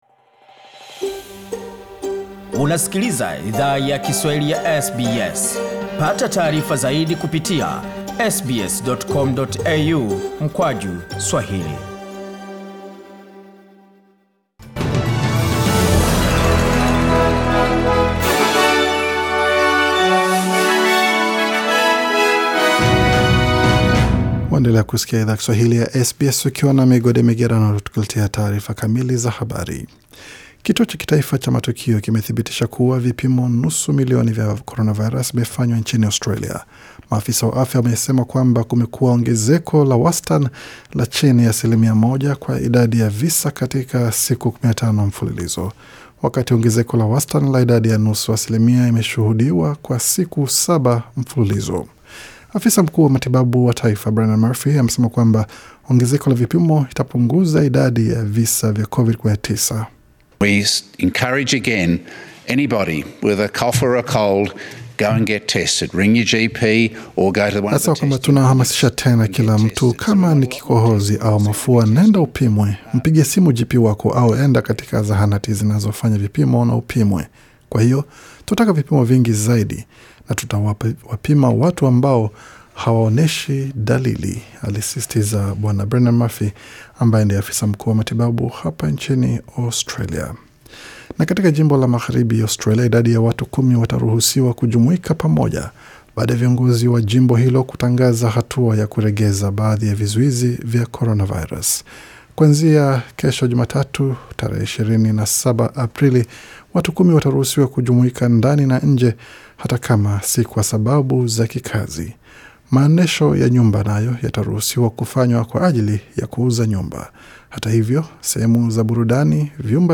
Taarifa za habari:Australia yakamilisha nusu milioni ya vipimo vya COVID-19